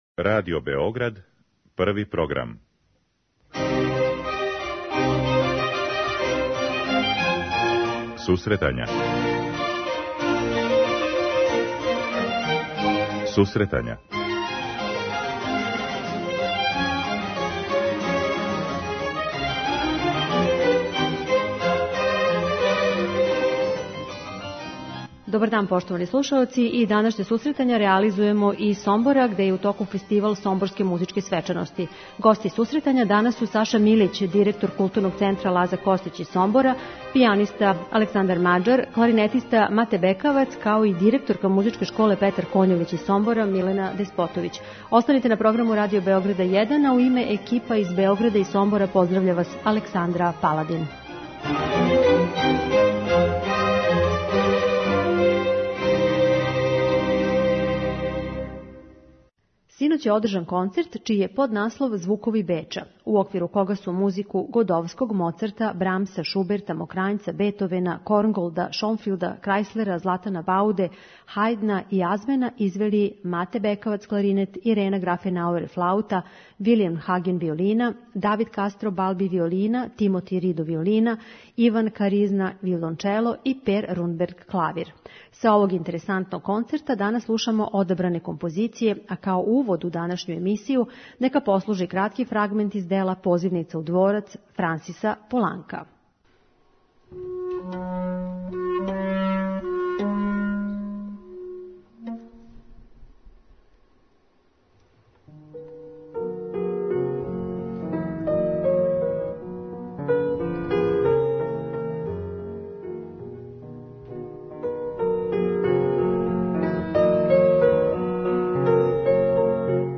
'Сомборске музичке свечаности', један од настаријих музичких фестивала у Србији, и ове године окупља интересантне уметнике.